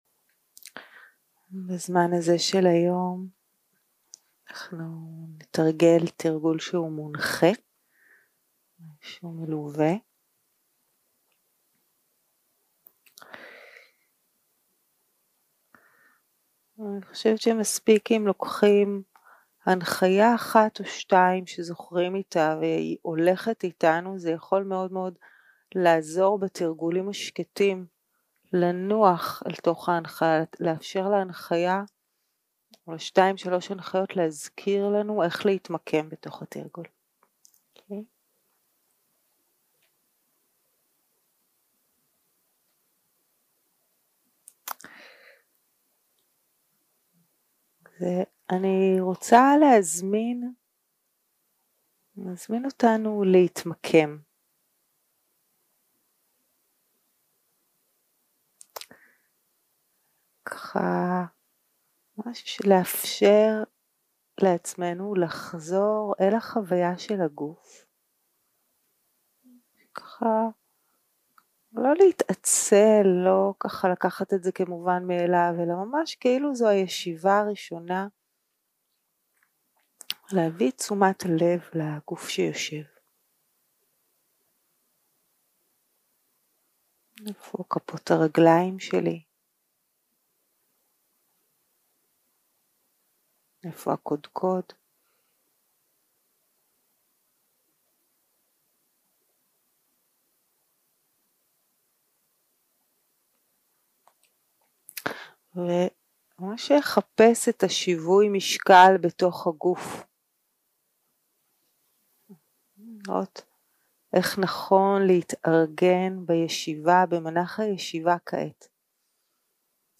יום 2 - הקלטה 3 - צהרים - מדיטציה מונחית
Dharma type: Guided meditation